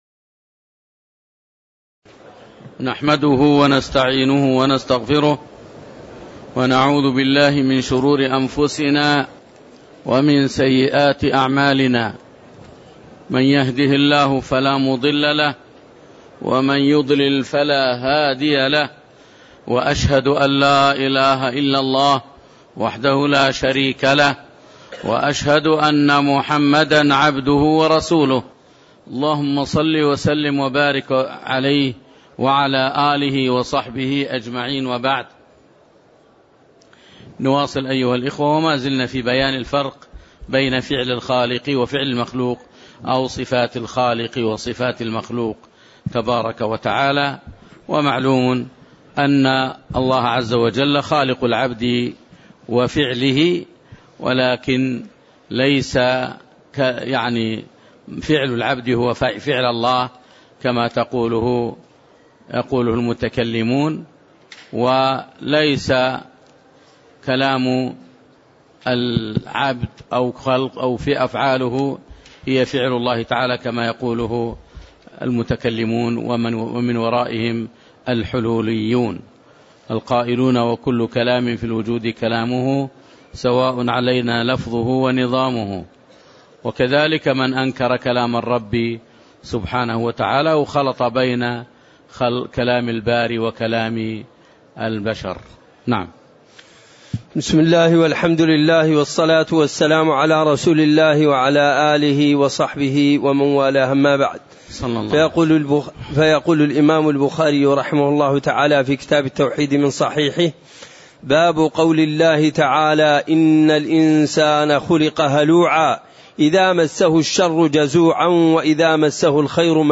تاريخ النشر ٢٦ ربيع الثاني ١٤٣٦ هـ المكان: المسجد النبوي الشيخ